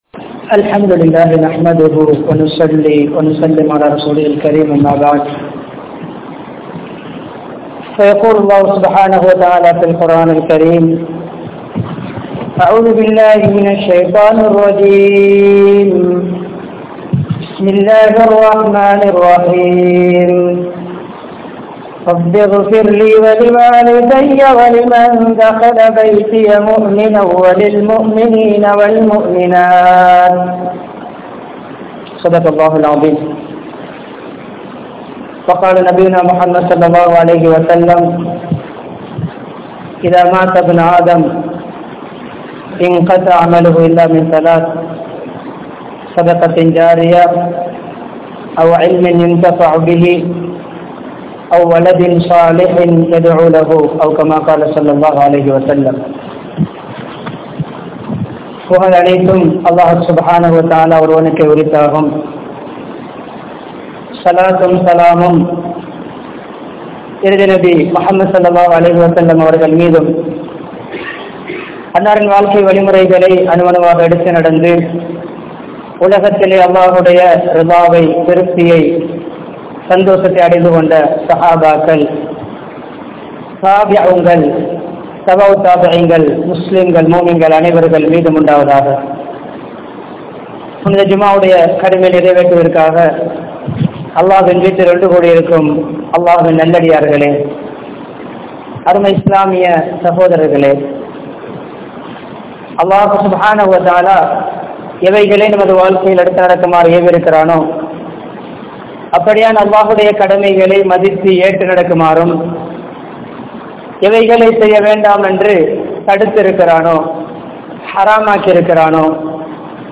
Maraniththa Petroarukkaaana Kadamaihal (மரணித்த பெற்றோருக்கான கடமைகள்) | Audio Bayans | All Ceylon Muslim Youth Community | Addalaichenai
Panadura, Gorakana Jumuah Masjith